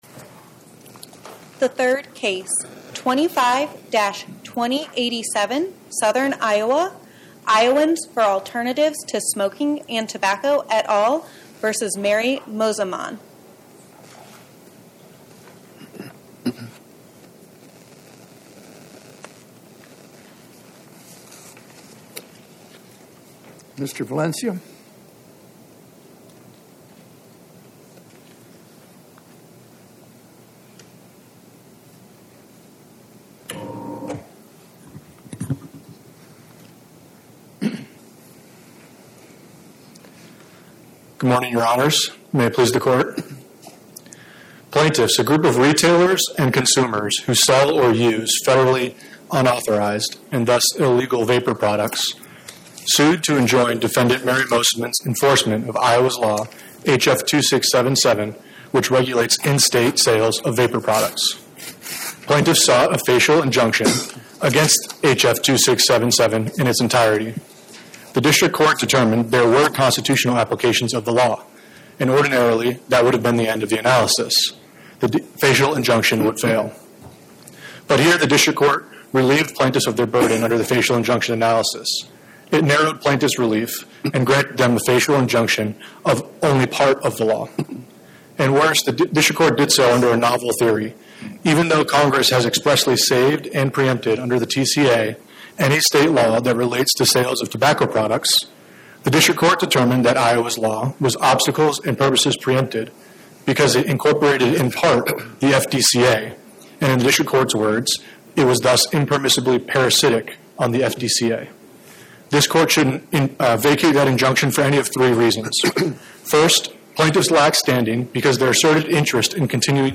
My Sentiment & Notes 25-2087: Iowans for Alternatives vs Mary Mosiman Podcast: Oral Arguments from the Eighth Circuit U.S. Court of Appeals Published On: Thu Jan 15 2026 Description: Oral argument argued before the Eighth Circuit U.S. Court of Appeals on or about 01/15/2026